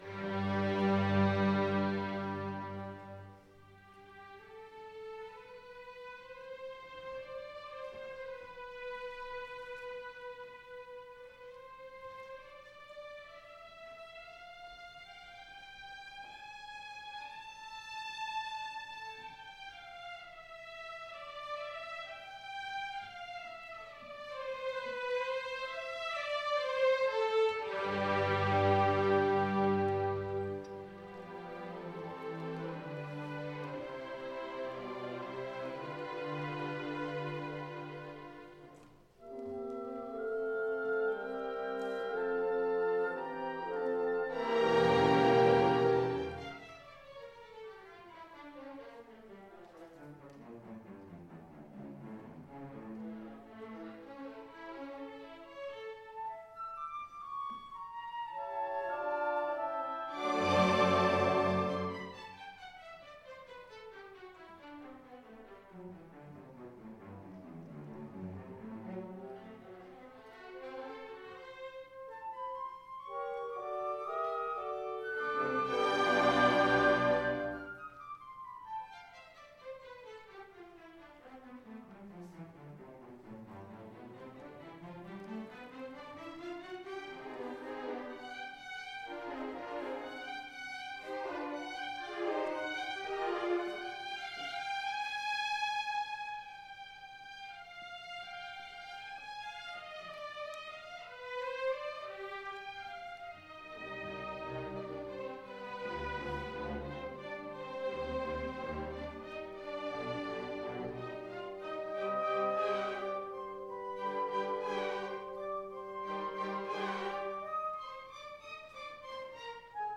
Orchestra  (View more Intermediate Orchestra Music)
Classical (View more Classical Orchestra Music)
Audio: Chicago - University of Chicago Symphony